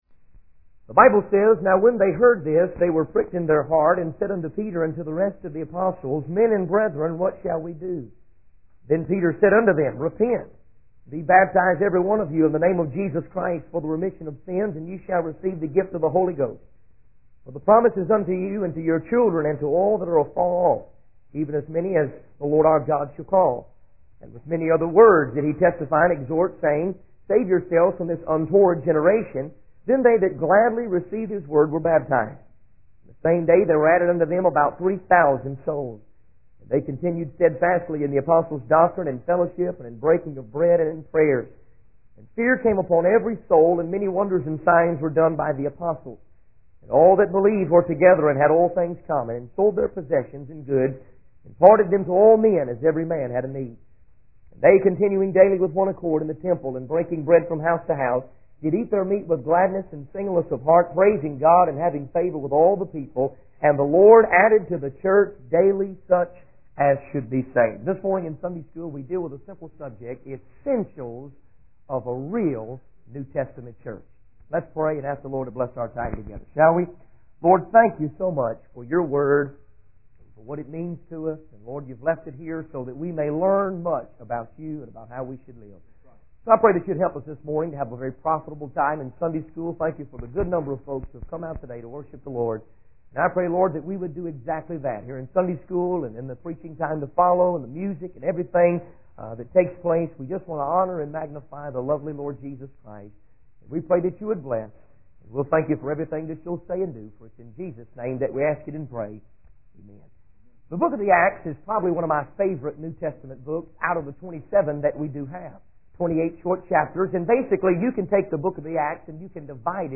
In this sermon, the preacher emphasizes the importance of reverent worship and the preaching of the Word of God.